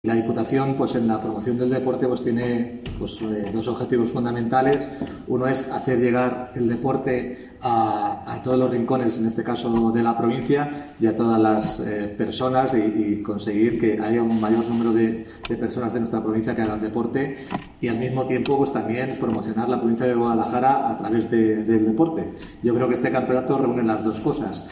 Declaraciones del diputado provincial de Deporte y Juventud, Marco Antonio Campos